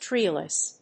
音節trée・less 発音記号・読み方
/ˈtrilʌs(米国英語), ˈtri:lʌs(英国英語)/